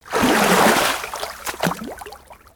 water-splash-4.ogg